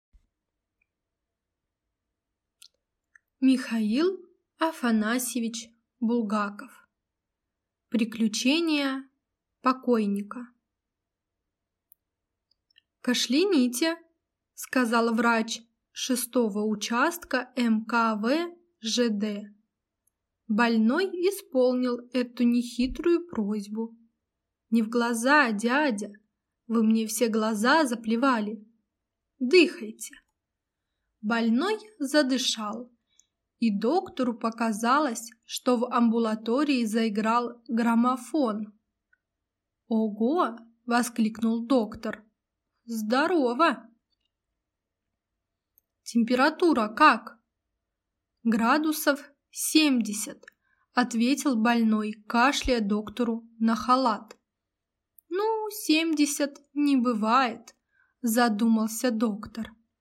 Аудиокнига Приключения покойника | Библиотека аудиокниг